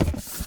sfx_cardboard_drop.ogg